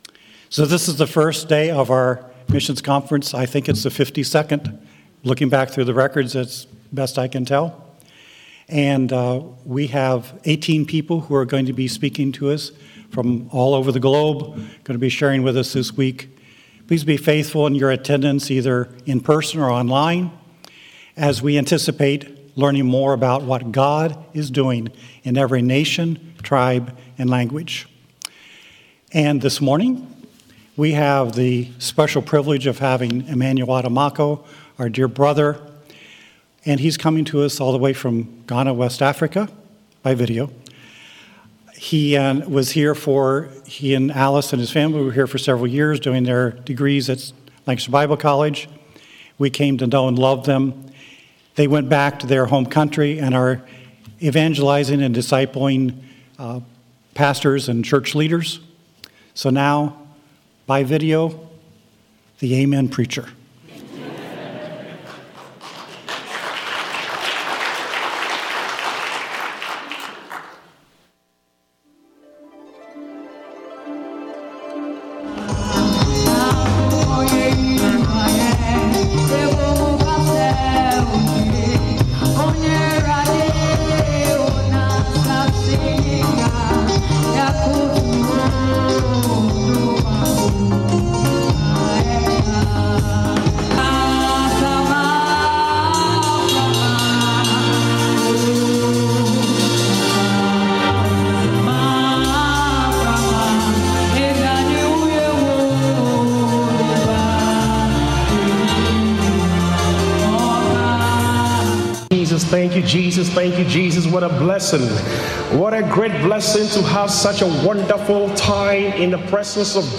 Sermon
2021 Global Outreach Conference